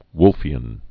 (wlfē-ən)